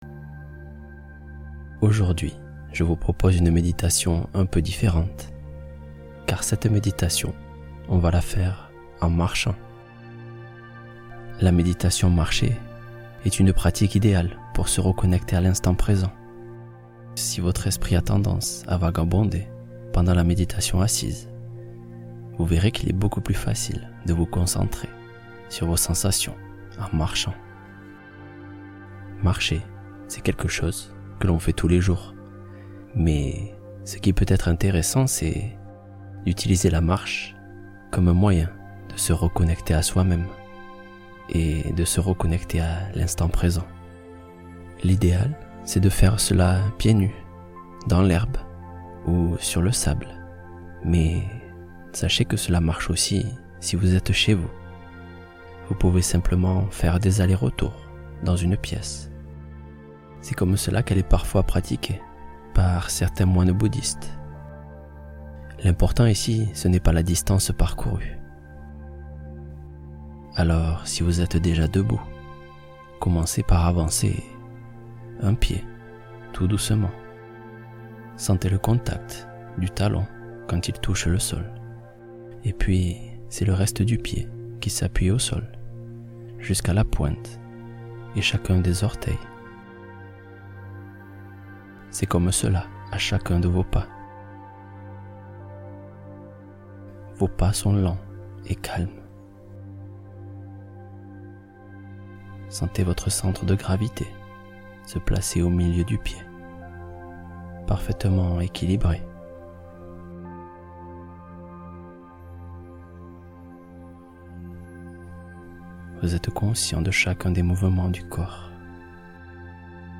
Retrouvez le Calme en Marchant (Méditation Simple qui Fonctionne)